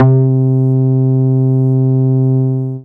MUTE BRASS.wav